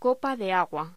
Locución: Copa de agua
voz
Sonidos: Hostelería